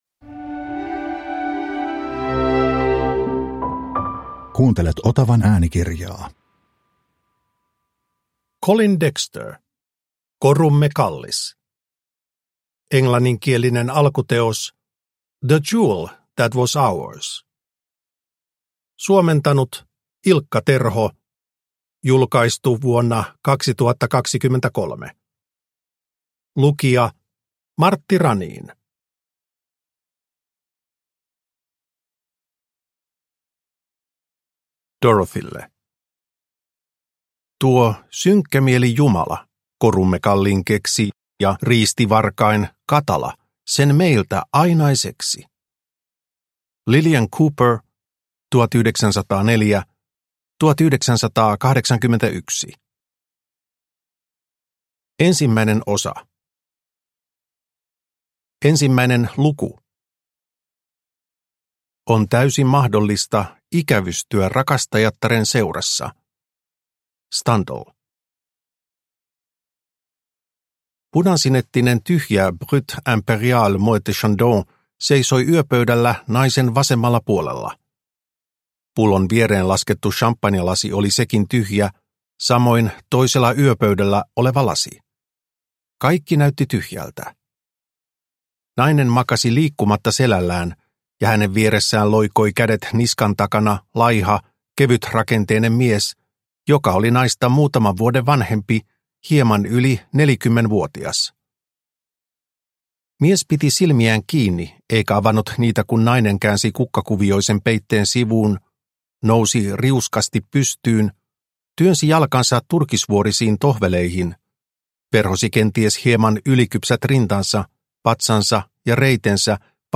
Korumme kallis – Ljudbok – Laddas ner